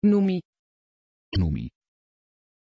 Pronunciation of Noëmie
pronunciation-noëmie-nl.mp3